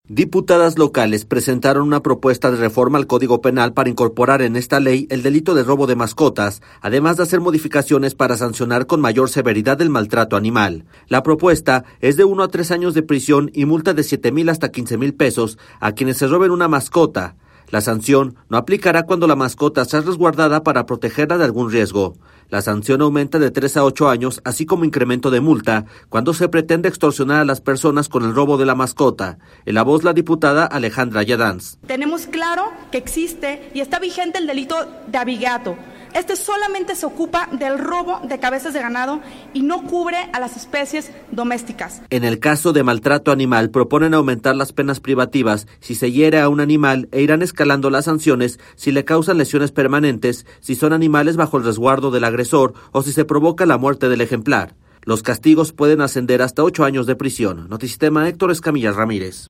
En la voz la diputada Alejandra Giadans.